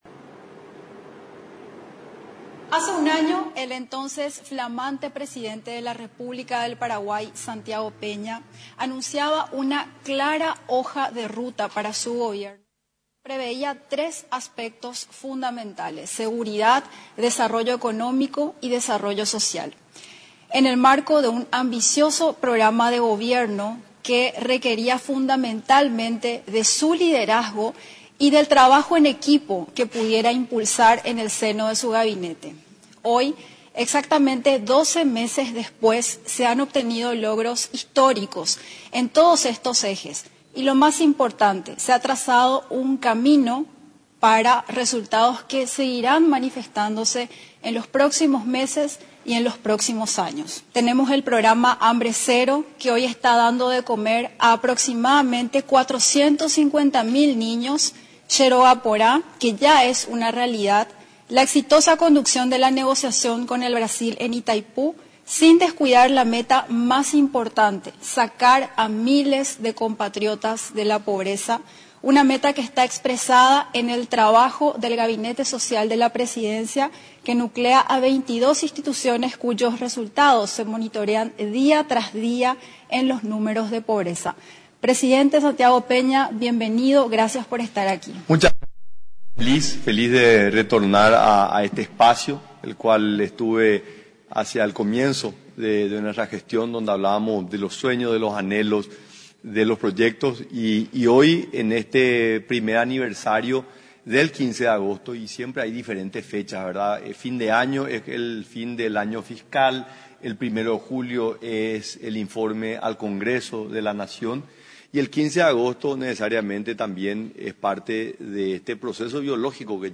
Este trabajo inició luego de las elecciones del 30 de abril, el 2 de mayo, con el trabajo con el equipo de transición que derivó en la primera reforma: la creación de la Dirección de Ingresos Tributarios (DNIT), dijo Peña en conversación con la vocera de Gobierno, Paula Carro.